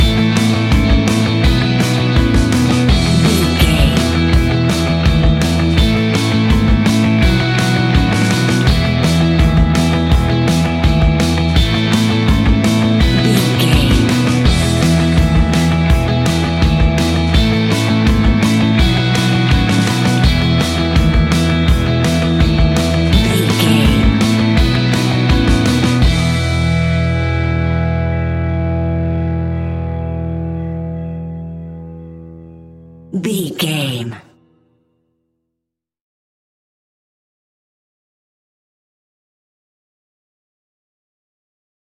Ionian/Major
pop rock
energetic
uplifting
instrumentals
indie pop rock music
upbeat
groovy
guitars
bass
drums
piano
organ